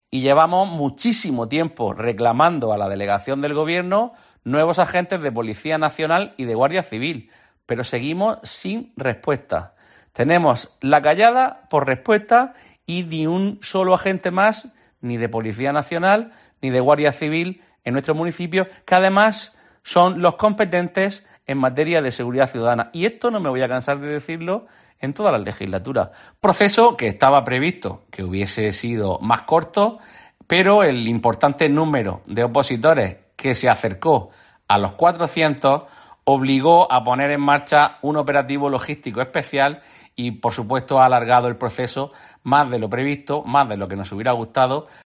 Juan Miguel Bayonas, edil Seguridad Ciudadana de Ayuntamiento de Lorca